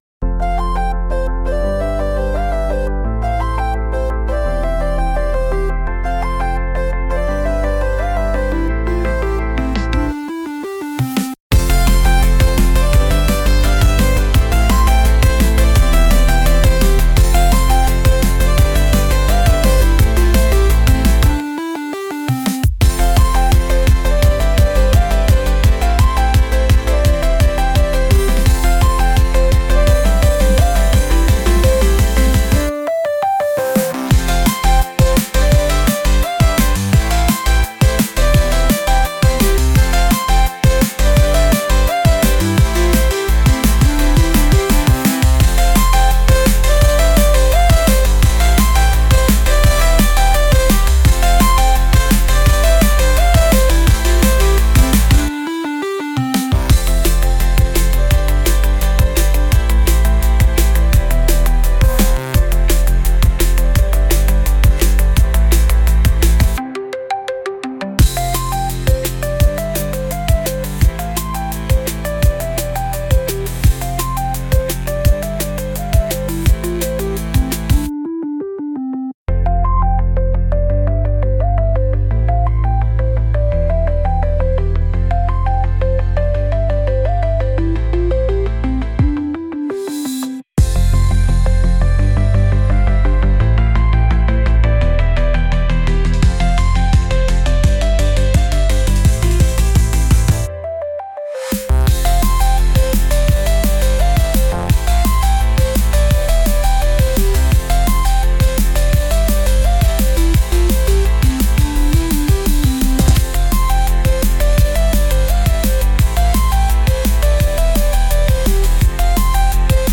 Genre: Hyperpop Mood: Upbeat Editor's Choice